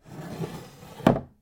桐タンス空引出し閉
cl_chest_drawer3.mp3